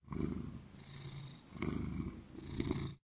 purr1.ogg